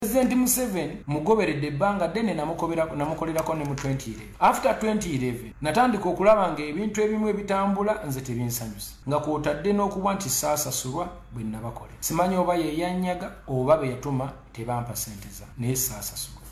Bw’abadde ayogerako eri abawagizi be ng’asinzira ku mukutu ogwa Face Book, kabuze kati akulukuse amaziga bw’ategeezeza, nti bannayuganda basukkiridde okweyawulamu, ekivudedeko okumuvuma buli lunnaku.
Eddoboozi lya Kenzo
Kenzo-M7.mp3